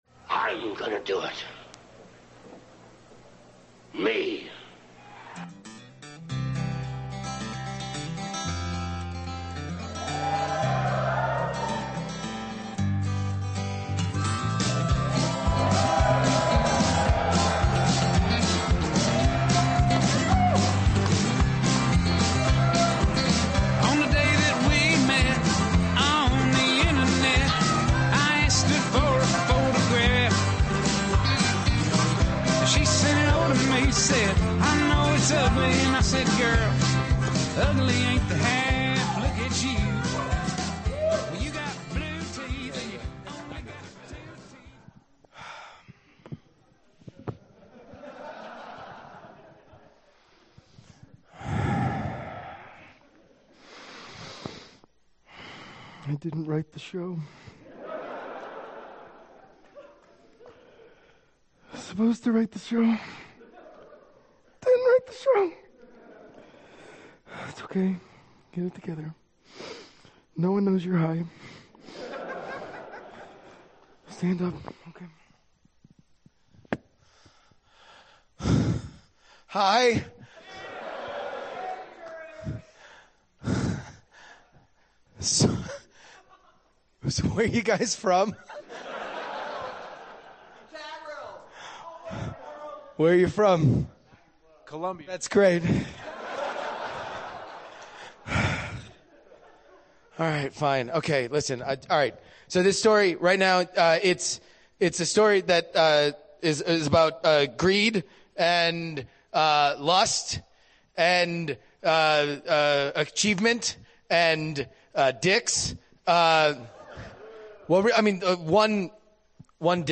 Live from Nerdtacular 2015!